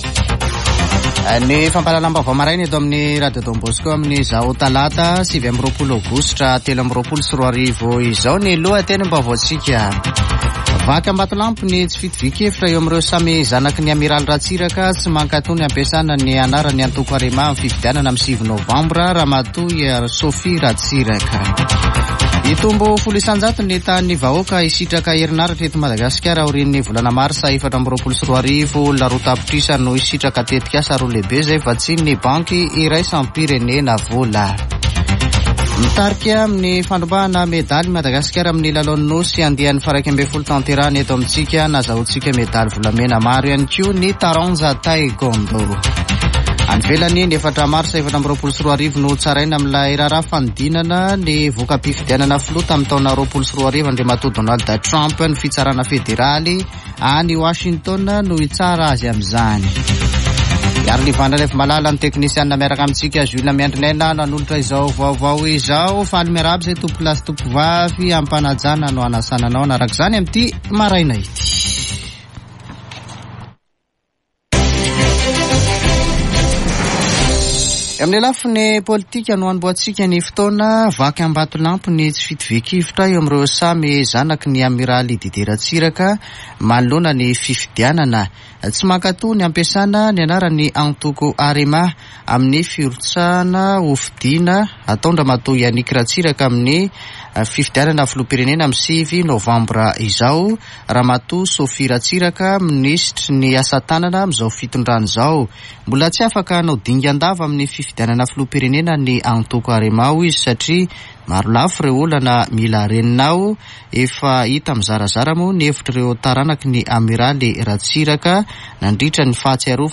[Vaovao maraina] Talata 29 aogositra 2023